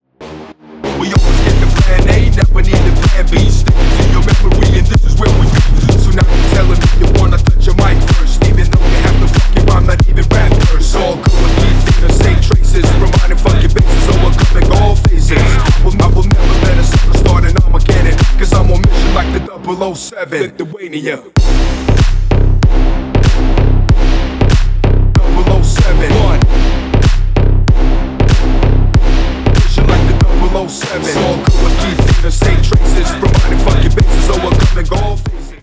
Рэп и Хип Хоп
клубные # громкие